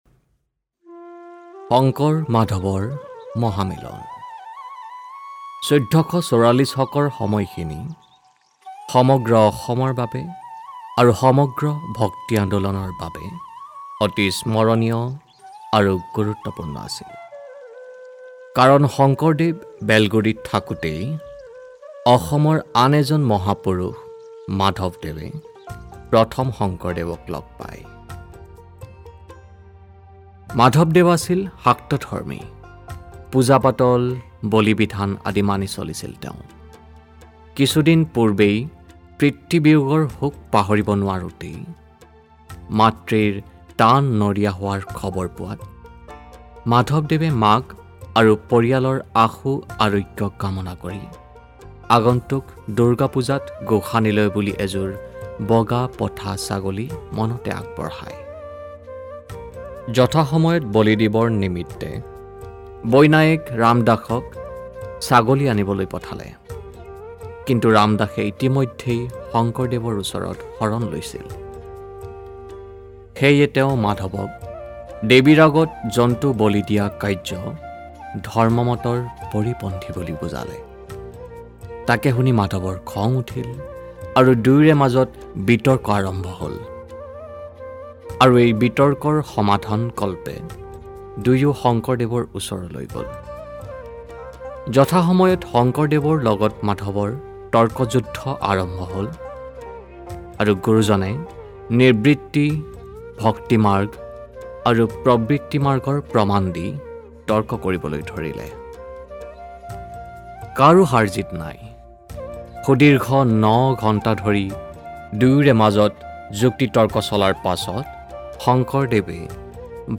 Professional Assamese Voice Artiste and Translator ....